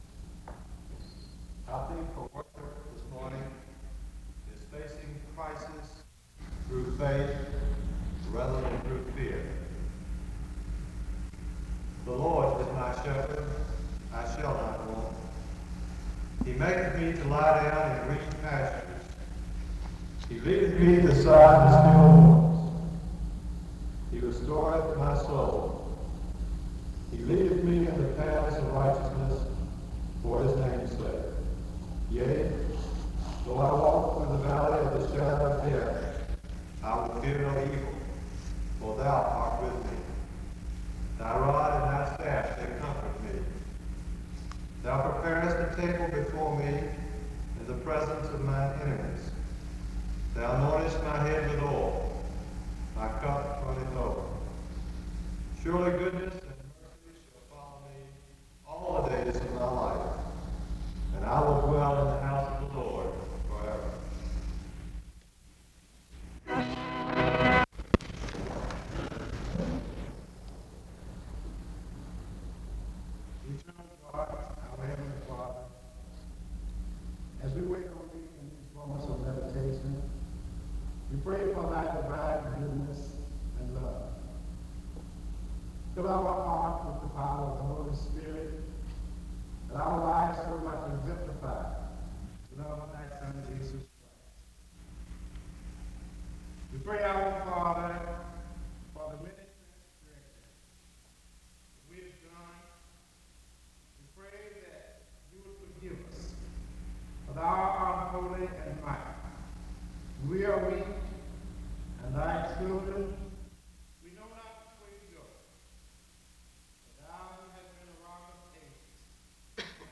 The service begins with an opening scripture reading from 0:00-1:04. A prayer is offered from 1:14-4:00. An introduction to the speaker is given from 4:06-6:30.
SEBTS Chapel and Special Event Recordings SEBTS Chapel and Special Event Recordings